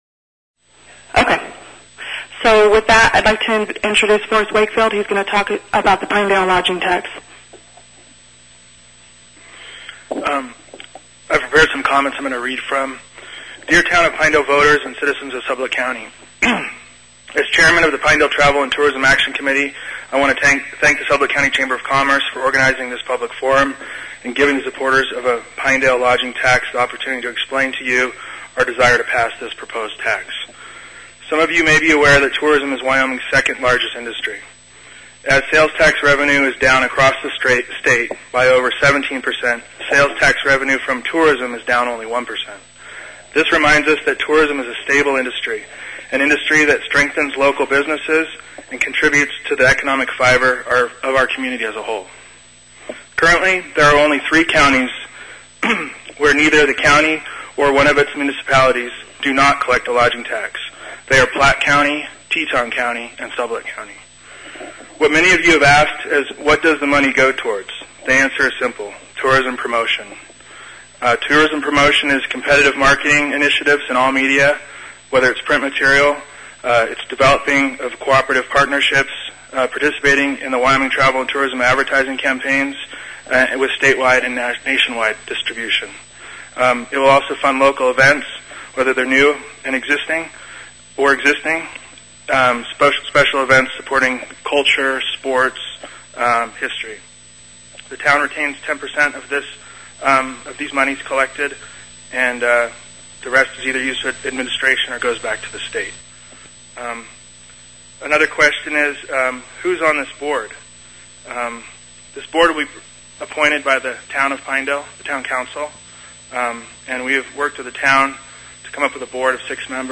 Below are the audio files from the Wednesday, April 28, 2010 Candidate Forum in the Lovatt Room of the Sublette County Library in Pinedale.
Reading a letter in support of the proposed 3% Pinedale Lodging Tax